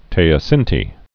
(tāə-sĭntē, tē-, tĕō-sēntĕ)